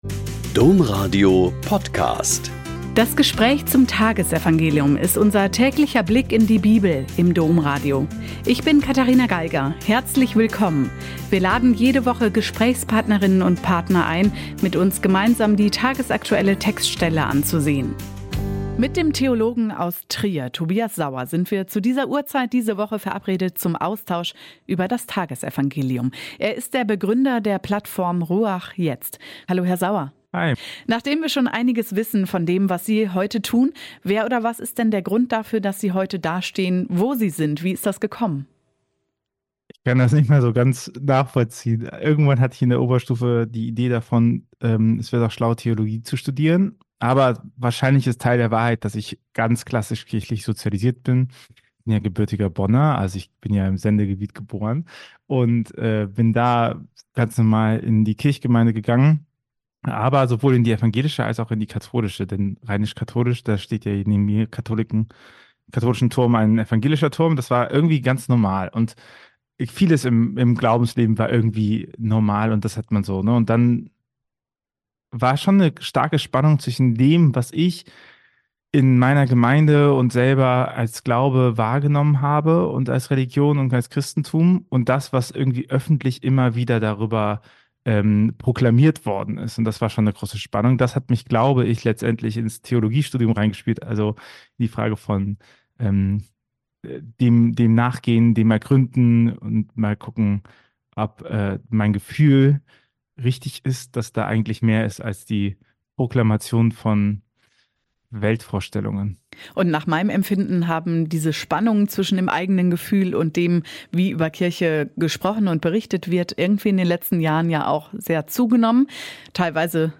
Mt 11,7b.11-15 - Gespräch